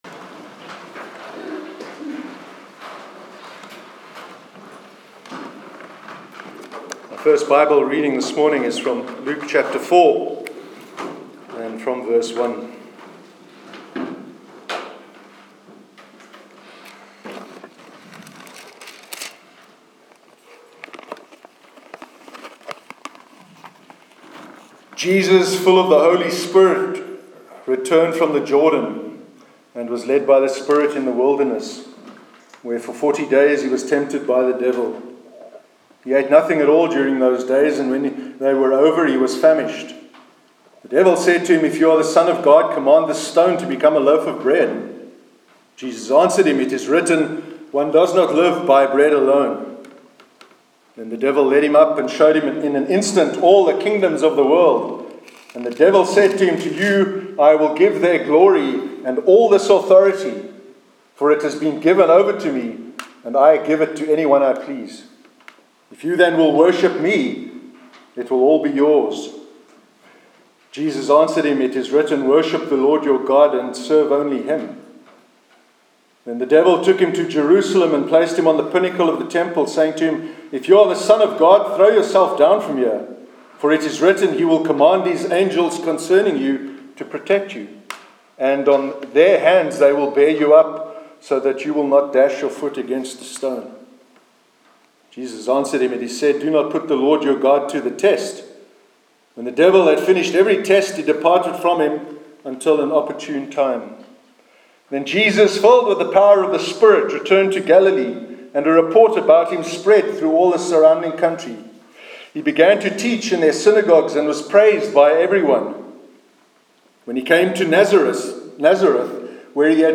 Sermon on the meaning of the Cross 11th June 2017